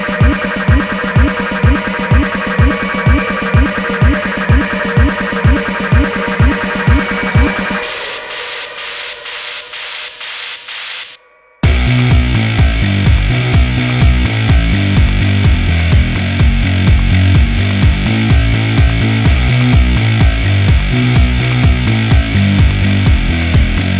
no Backing Vocals R'n'B / Hip Hop 2:57 Buy £1.50